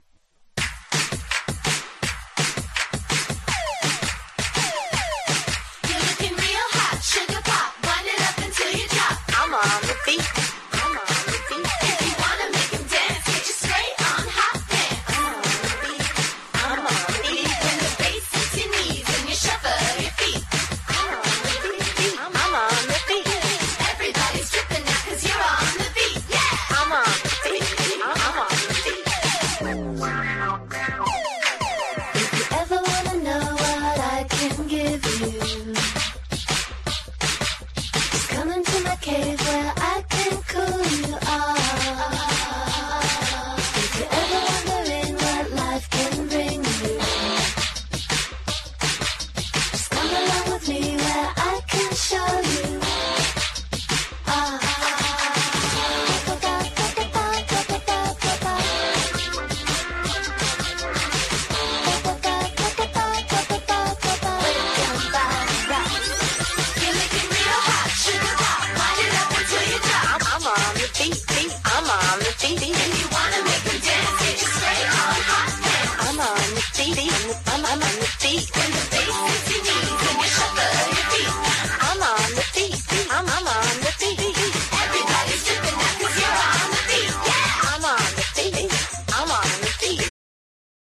超キャッチーなガールズ・デュオ！！
# INDIE DANCE